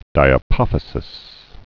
(dīə-pŏfĭ-sĭs)